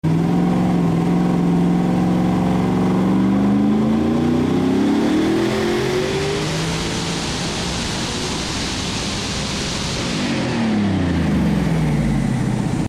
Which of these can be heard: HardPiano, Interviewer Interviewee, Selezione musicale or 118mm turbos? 118mm turbos